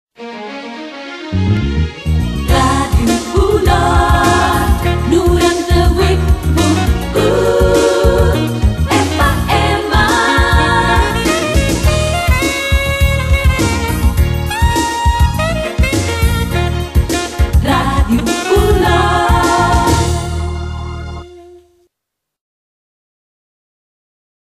Indicatiu de l'emissora al 98.1 FM